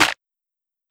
Clap (Hyyerr).wav